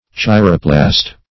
Search Result for " chiroplast" : The Collaborative International Dictionary of English v.0.48: Chiroplast \Chi"ro*plast\, n. [Gr.
chiroplast.mp3